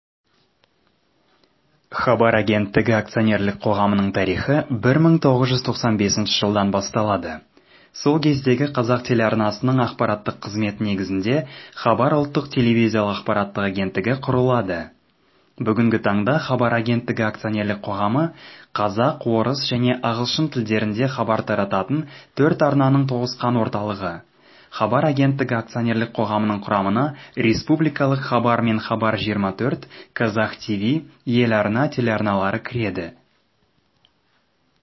• 4Kazakh Male No.1
Explanation